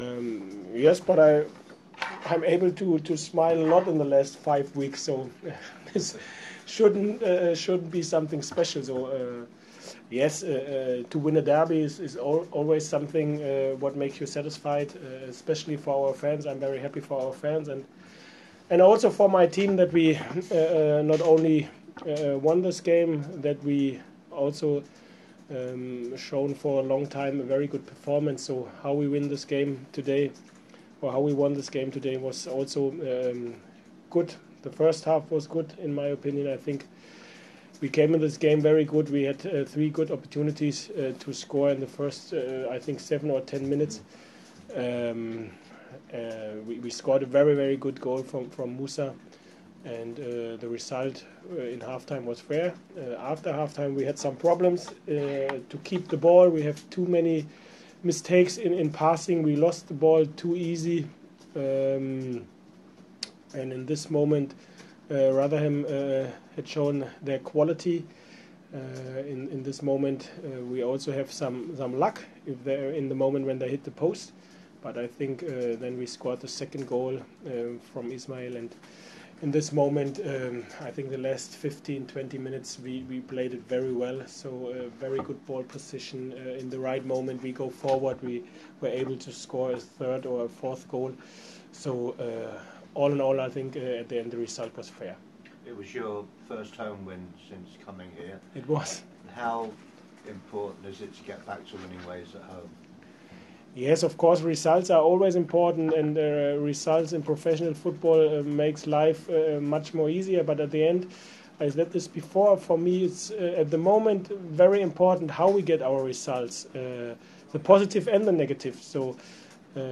Huddersfield Town Head Coach David Wagner speaks to Radio Yorkshire following his sides 2-0 win against Rotherham United.